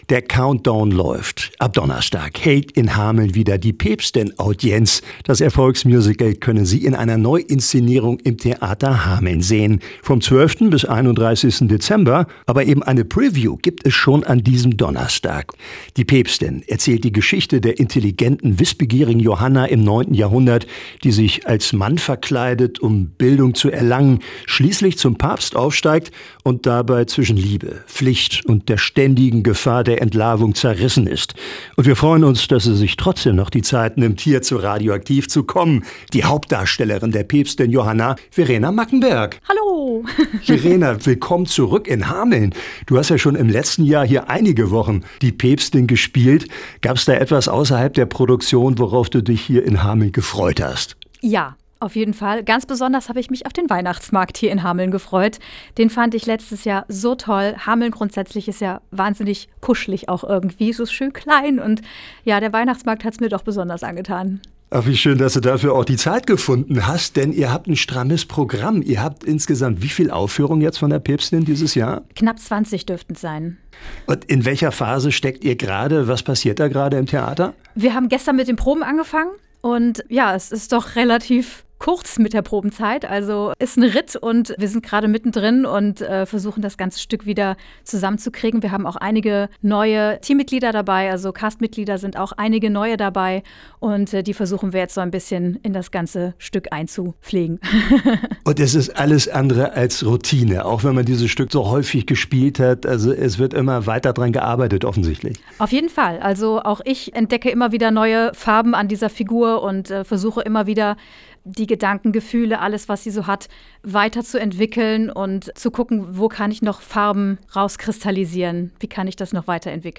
Aktuelle Lokalbeiträge